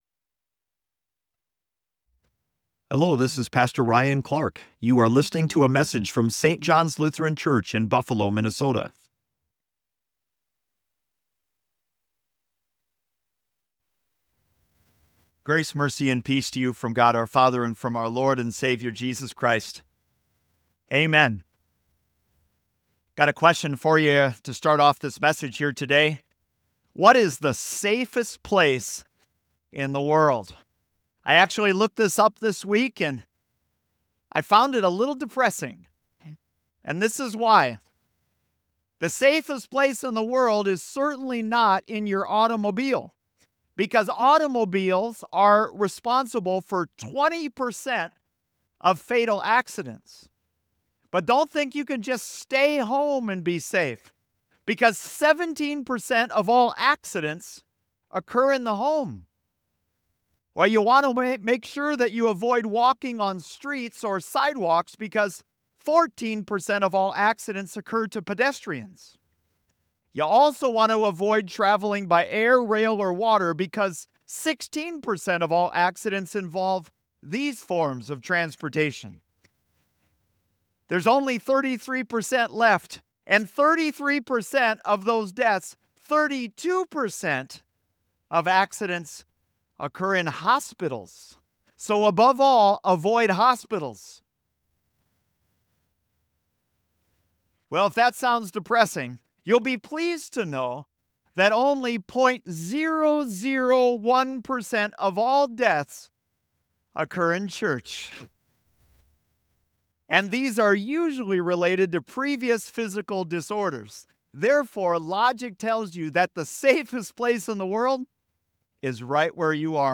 St. John's Lutheran Church